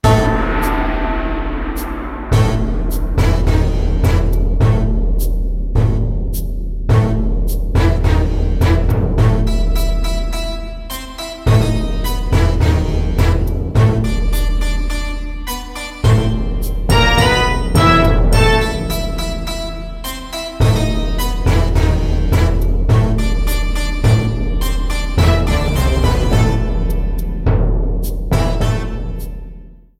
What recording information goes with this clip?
Ripped from the ISO Faded in the end